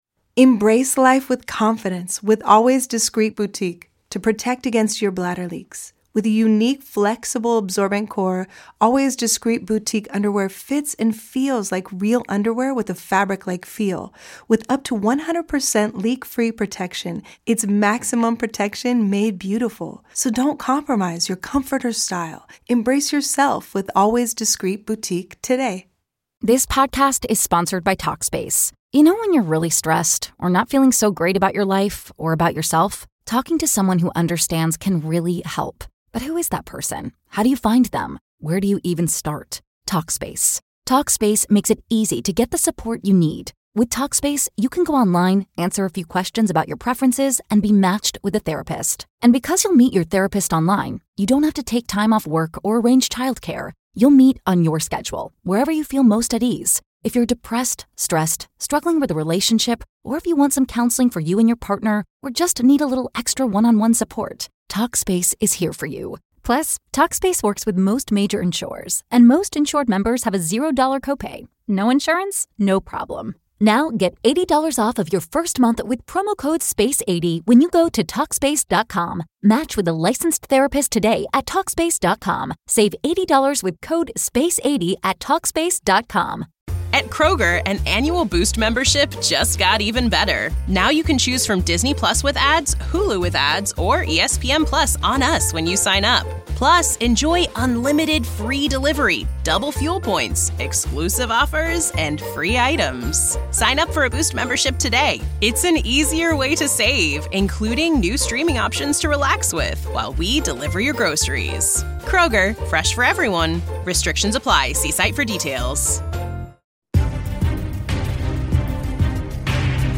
Hard Work - Epic Motivational Speech